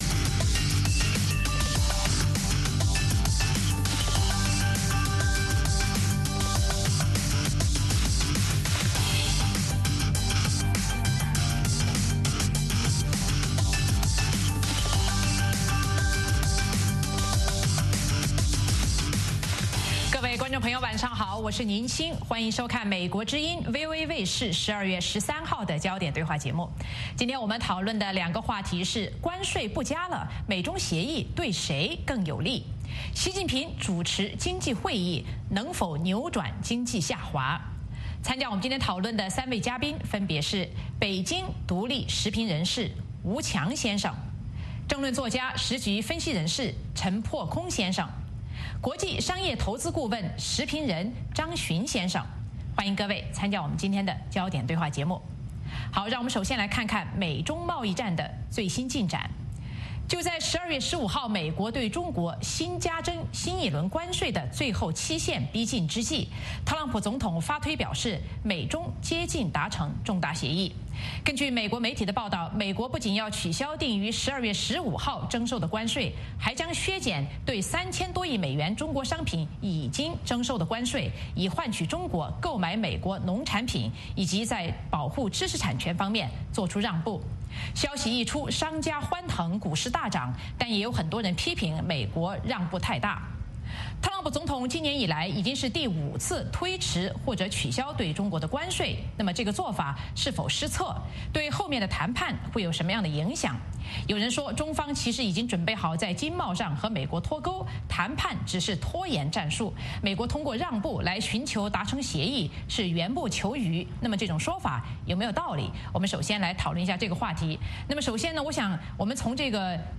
美国之音中文广播于北京时间早上6－7点重播“焦点对话”节目。《焦点对话》节目追踪国际大事、聚焦时事热点。邀请多位嘉宾对新闻事件进行分析、解读和评论。